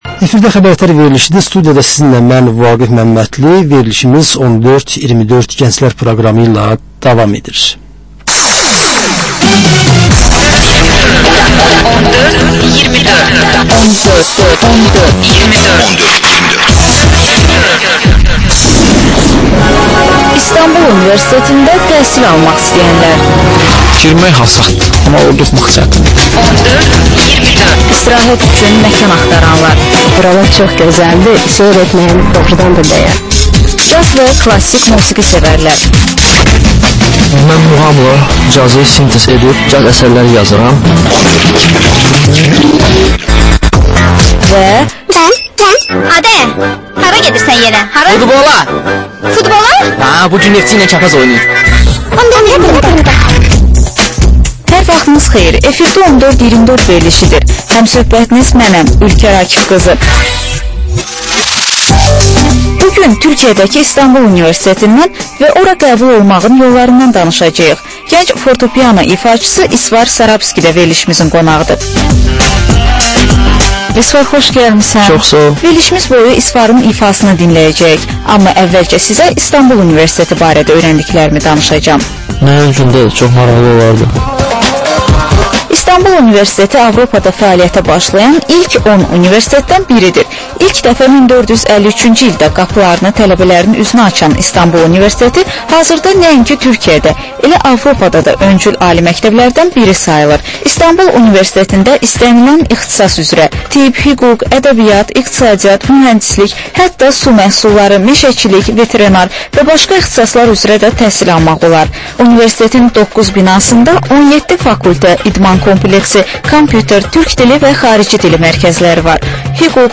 Reportajç müsahibə, təhlil